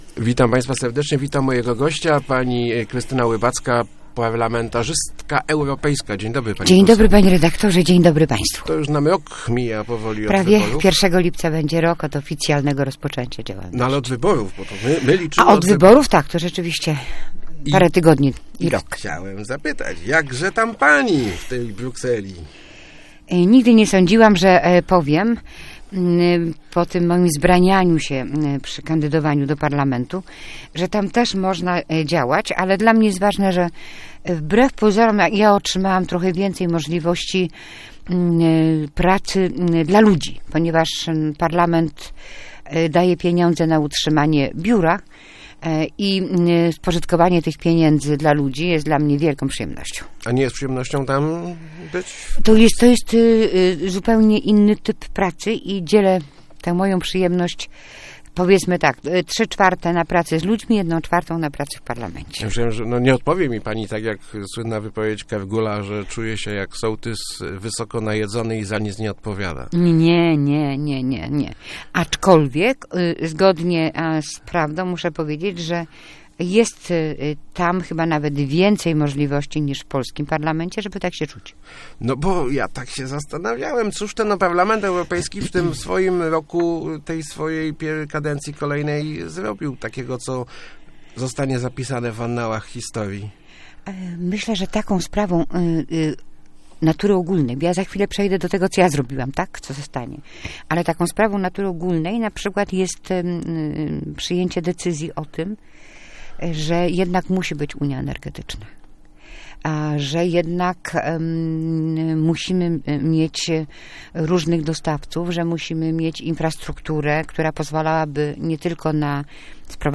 Nasza gospodarka nie może pozwolić sobie na odejscie od węgla - mówiła w Rozmowach Elki europosłanka Krystyna Łybacka. Zastrzegła jednak, że jej zdaniem nie ma mowy na budowę kopalni węgla brunatnego w naszym regionie.